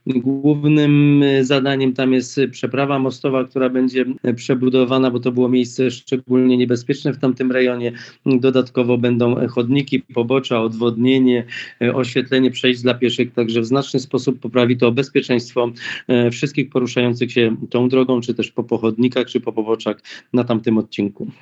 - Głównym zadaniem jest remont przeprawy mostowej. Było miejsce szczególnie niebezpieczne - mówi wójt gminy Oświęcim Mirosław Smolarek.